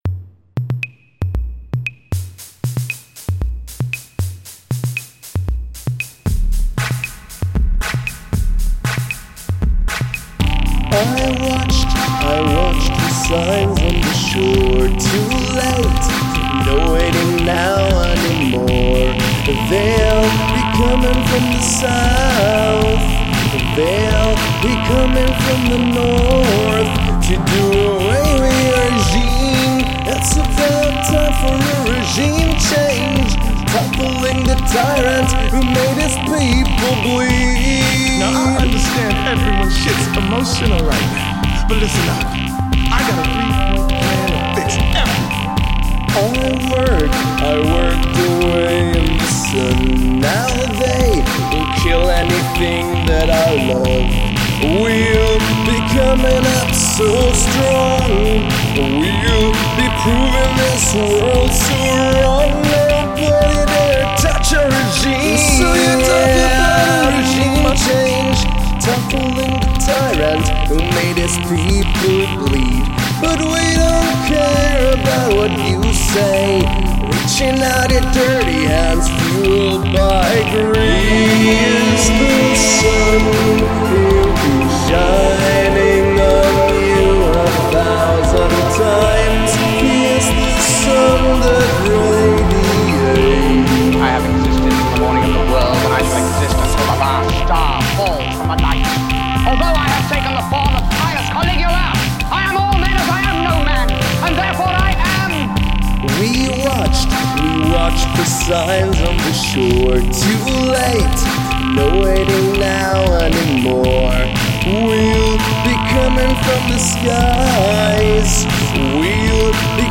Lot's of catchy bits but no standout hook.